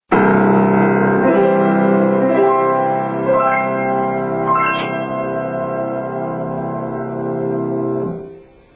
今回、比較的近いサイズのグランドピアノで弾き比べをする機会がありましたので、その音をお届けしたいと思います。
ピアノのボディー全体を鳴らして醸し出す、その柔らかい音は、「ウィンナー・トーン」を今に伝える「ウィーンの宝」とも言われます。
録音した音ですので、実際にピアノの近くで聴いたときのような鮮明な違いは確認しにくいかもしれません。